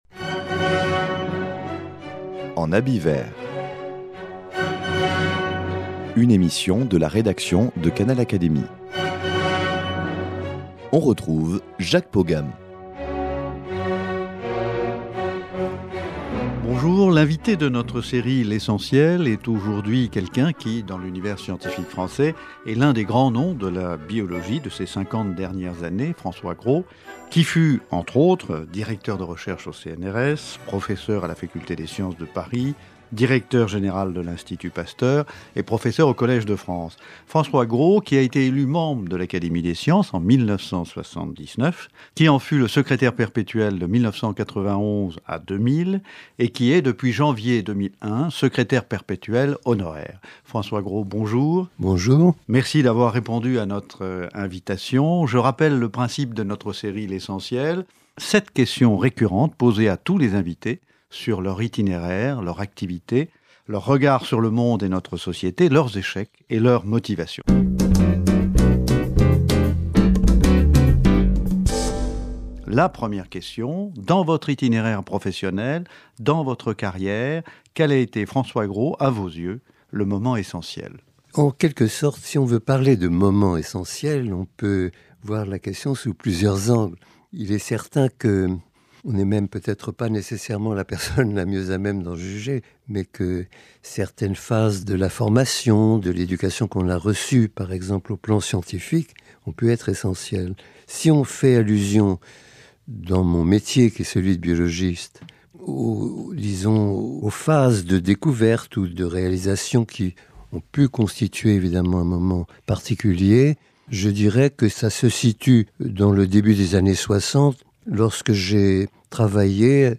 Rencontre avec un scientifique pour qui science et humanisme sont indissociables.